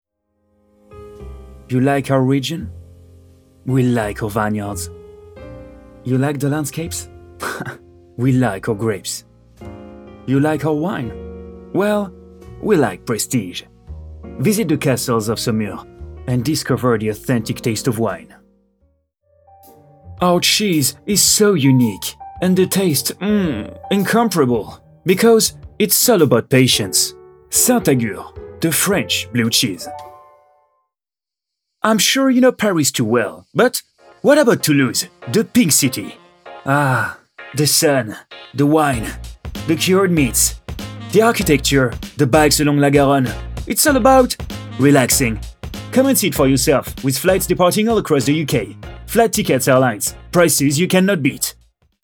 Male
Confident, Engaging, Friendly, Versatile
English French accent
I got my own professional home studio with a high quality recording set up. About my voice: I got a friendly, smooth and warm tone .
2023_English_French_accent_voice_reel_de....mp3
Microphone: TLM 102
Audio equipment: Universal Audio Apollo Twin MKII, Waves plug ins, Fully soundproof room